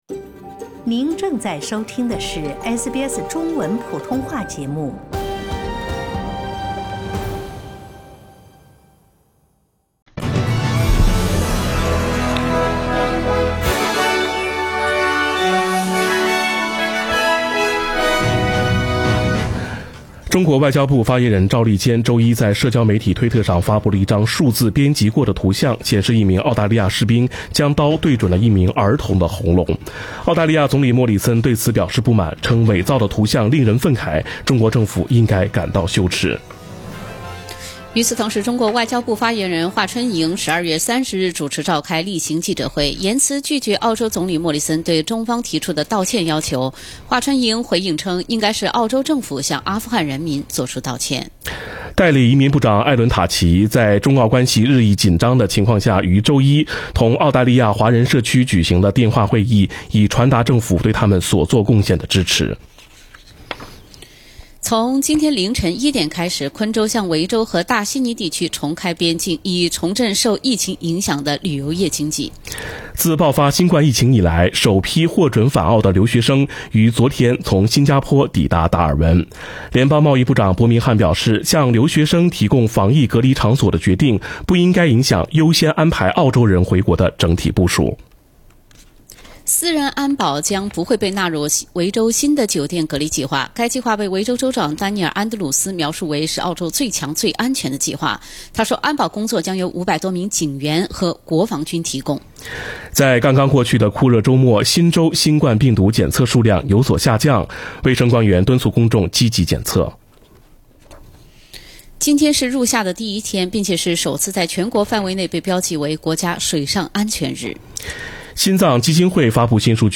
SBS早新聞（12月1日）
SBS Mandarin morning news Source: Getty Images